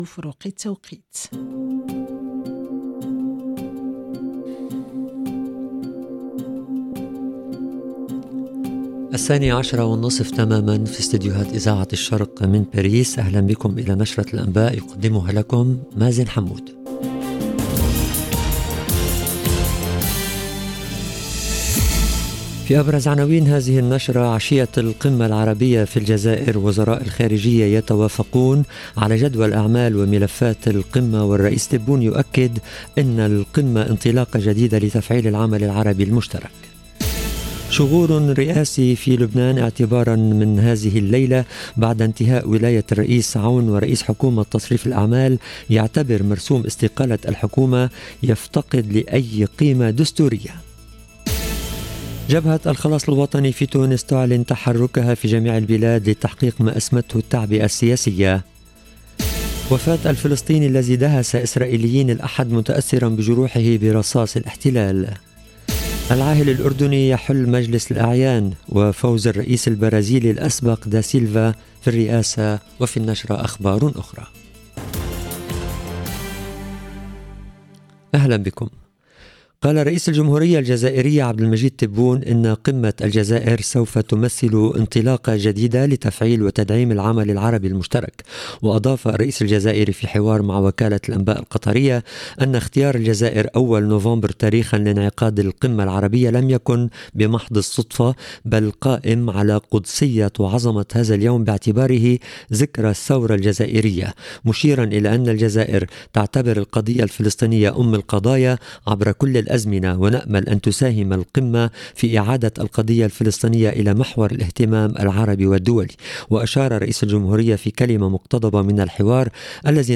LE JOURNAL DE 12H30 EN LANGUE ARABE DU 31/10/2022